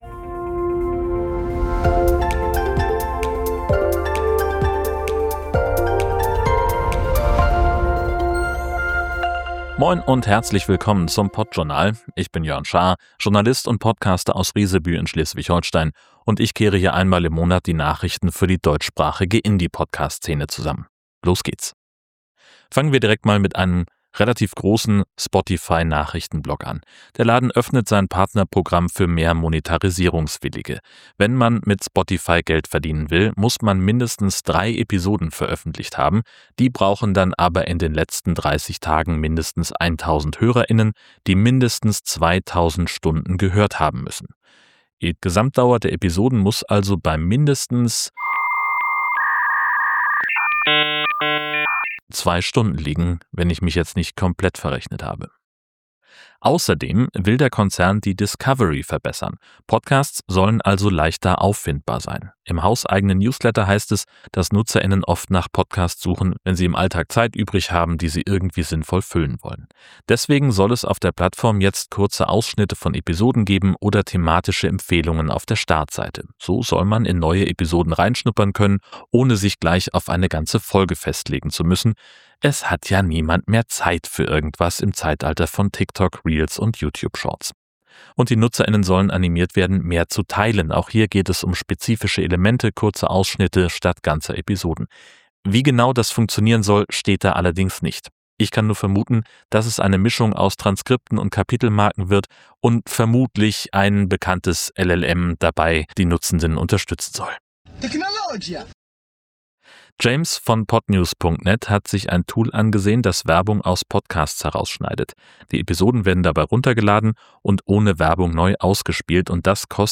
Neben dem “Technologia“-Meme habe ich “Clean and Pompous Fanfare
Trumpet“ und Dial-up_sound.mp3.flac von freesound benutzt.